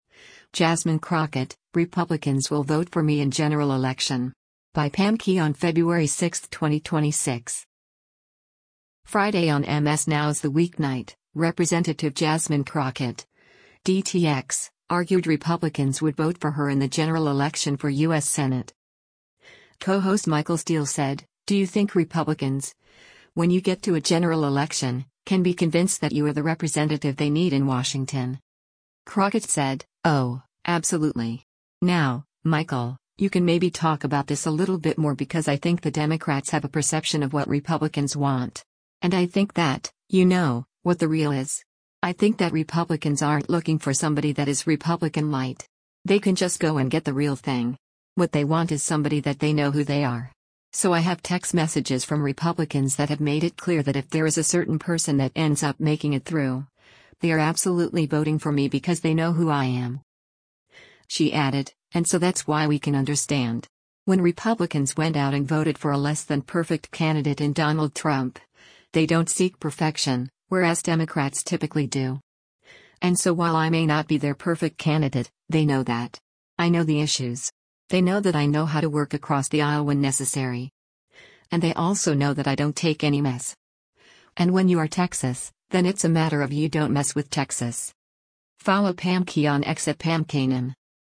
Friday on MS NOW’s “The Weeknight,” Rep. Jasmine Crockett (D-TX) argued Republicans would vote for her in the general election for U.S. Senate.